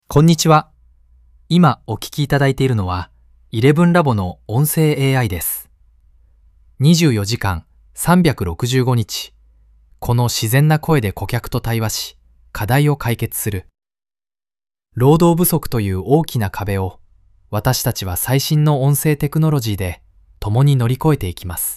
この人間らしい「TTS音声」が、他社との大きな差別化につながっていると考えています。